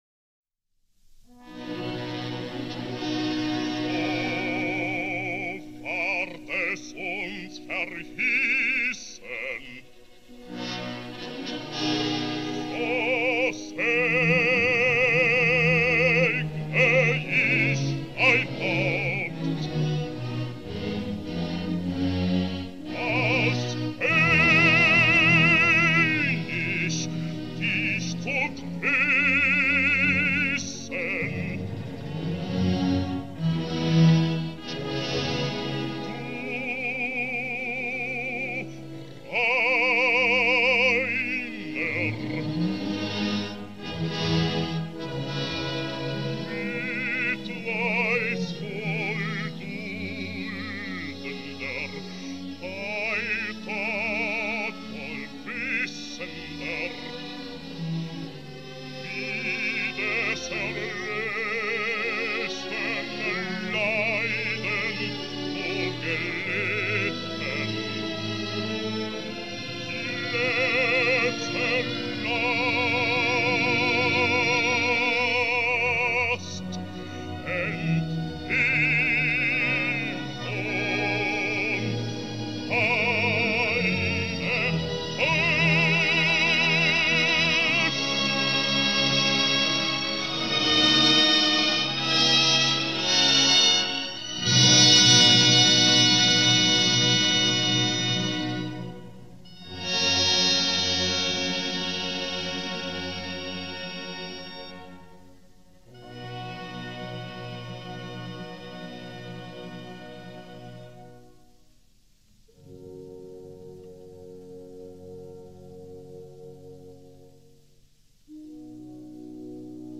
D’une manière générale, tous ces extraits, dont les plus anciens ont été réalisés du temps de l’enregistrement acoustique, sont très soignés pour leur époque et ont été réédités dans d’excellentes conditions. Parfois, pour le même enregistrement, les matrices de 78 tours employées et le remastering sont différents : les résultats produits peuvent s’avérer très variables, et, généralement, les remastérisations les plus récentes sont les meilleures : les ingénieurs du son ont réalisé d’énormes progrès dans le traitement des enregistrements anciens.